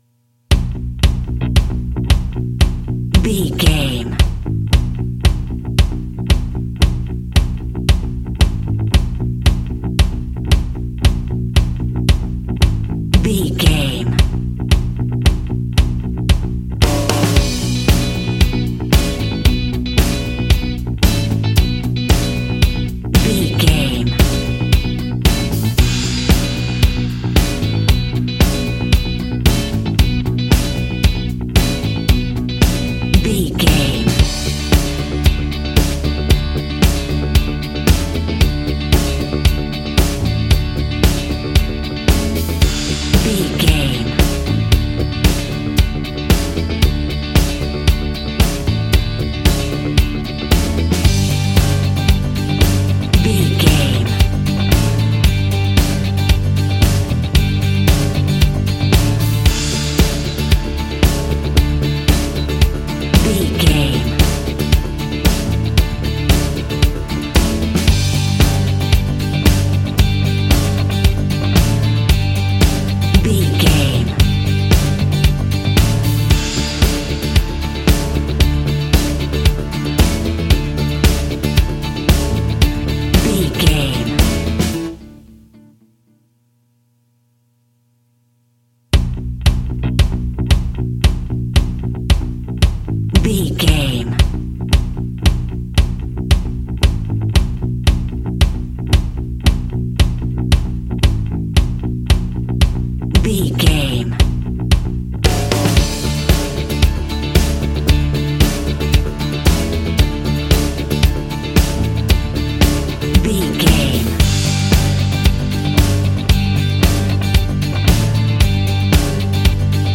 Ionian/Major
D
groovy
powerful
organ
drums
bass guitar
electric guitar
piano